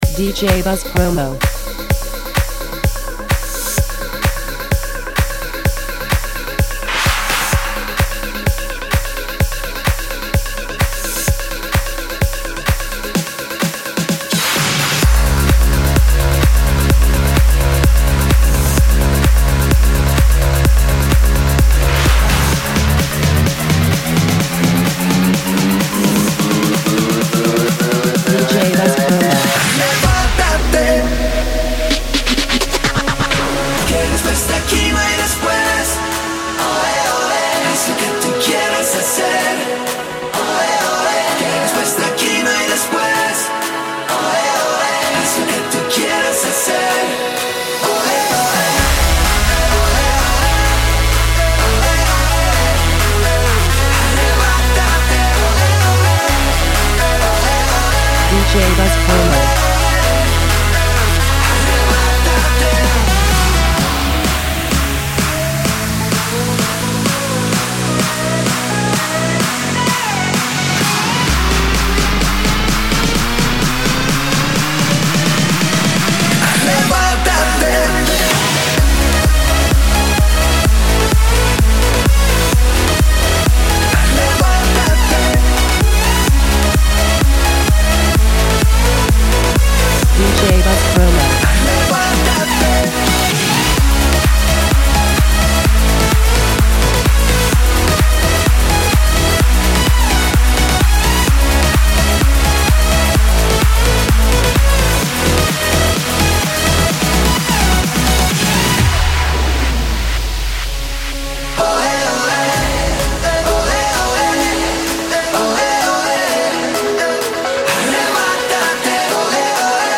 The Latino/Flamenco singer and performer